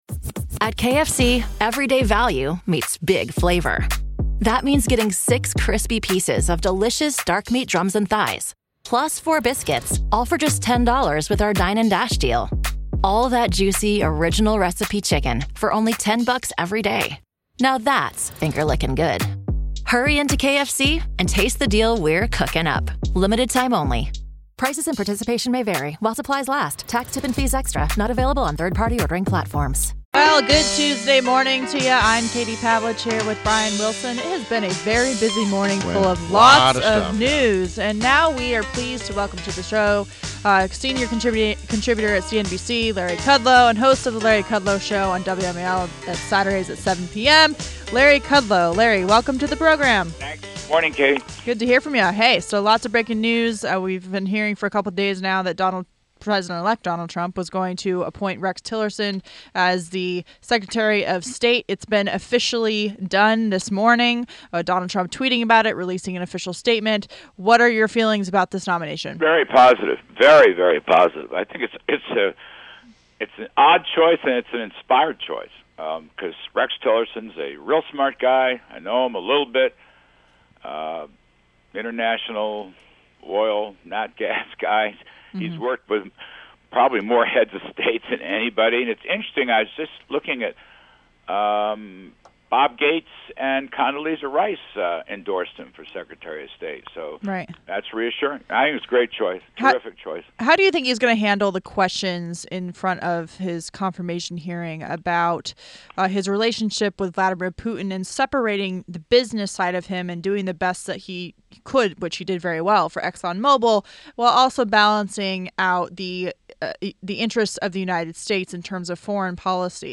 WMAL Interview - LARRY KUDLOW - 12.13.16
INTERVIEW -- LARRY KUDLOW - CNBC Senior Contributor and host of The Larry Kudlow Show on WMAL Saturdays at 7 pm